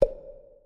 SFX_Dialog_04.wav